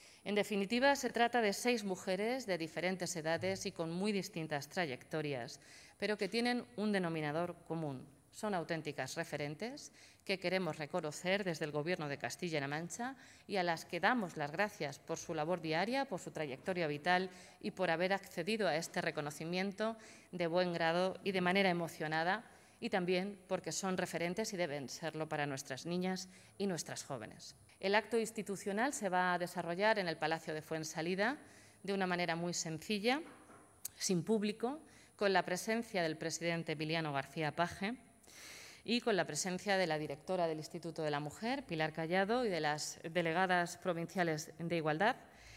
Presentación del acto institucional del Día Internacional de la Mujer.
Blanca Fernández desgrana los detalles de los actos del 8M - 18 febrero 2021
BLANCA-FERNANDEZ-ACTO-8M.mp3